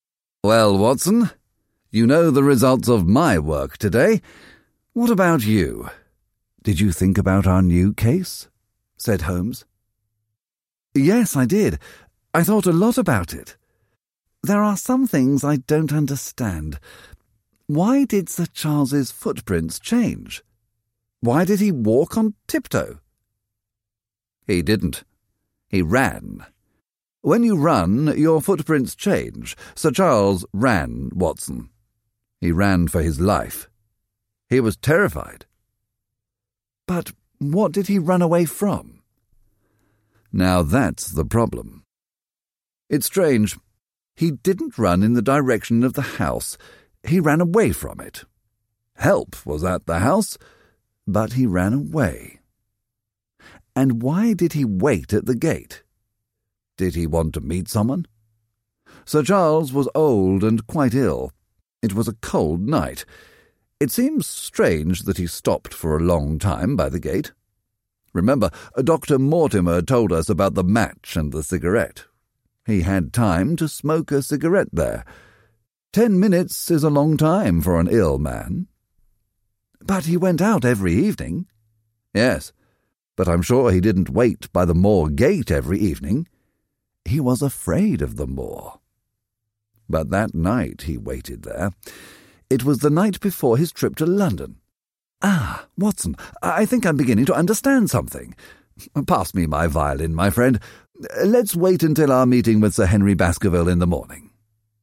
The Hound of the Baskervilles (EN) audiokniha
Ukázka z knihy